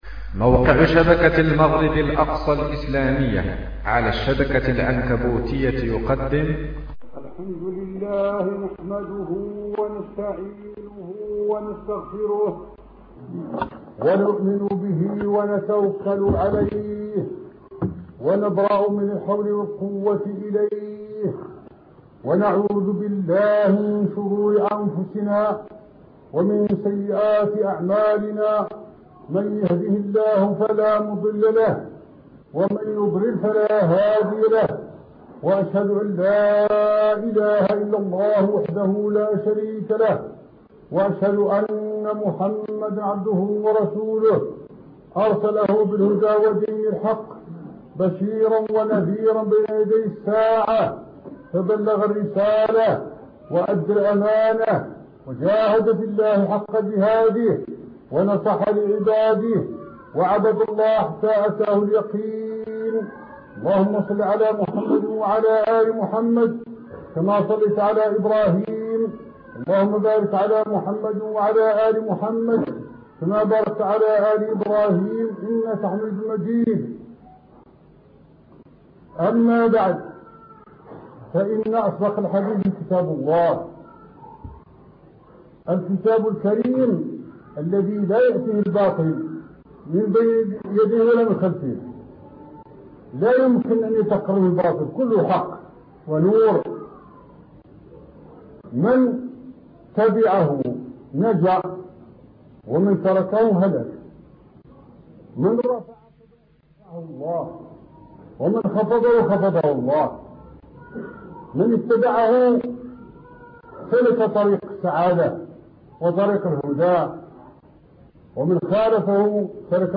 خطبة الوعيد للآكل بالقرآن والمفاخر والمرائي به الشيخ محمد تقي الدين الهلالي